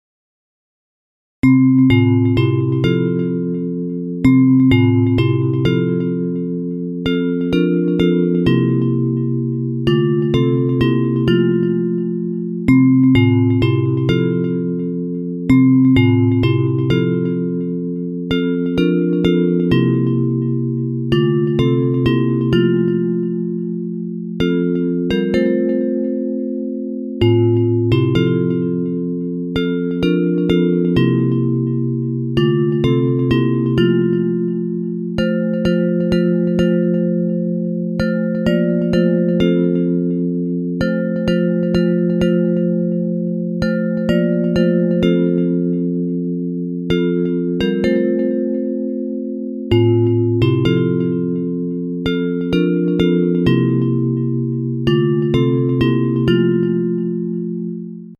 Bells Version